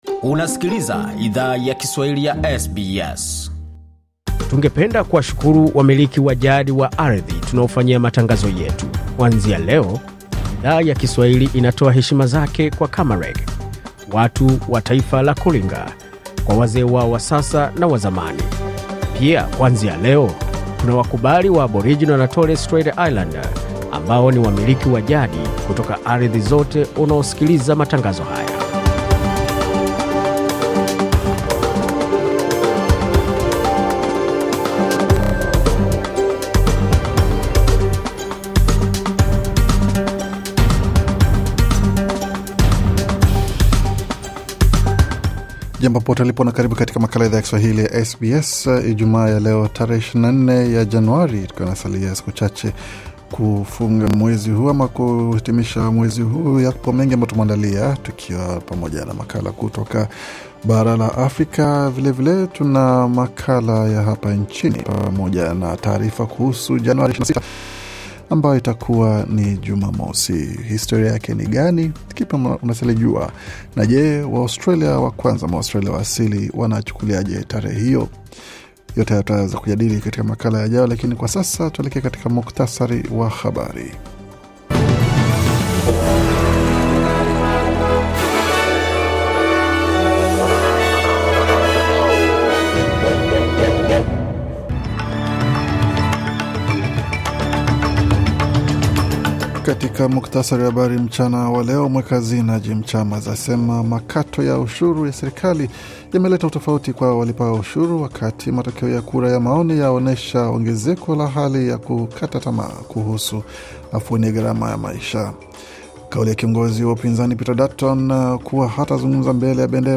Taarifa ya Habari 24 Januari 2025